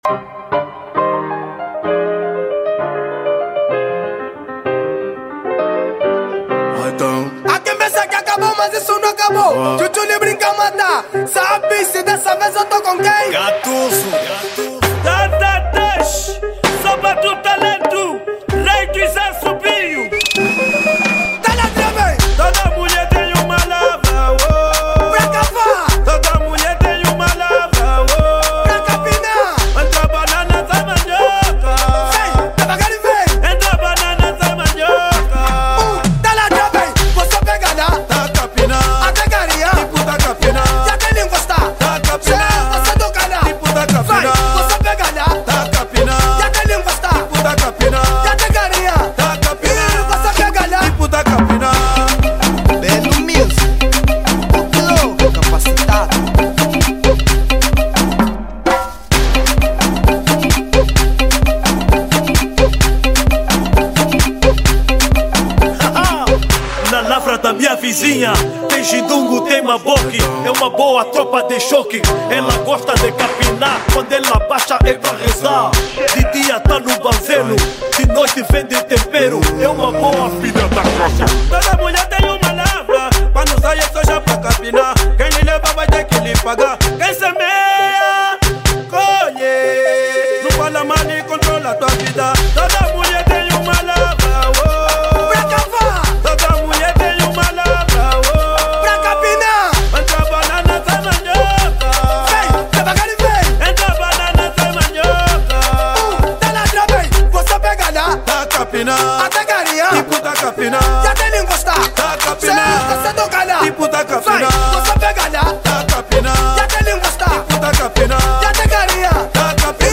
Género : Afro House